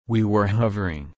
/ˈhɒ.və(ɹ)/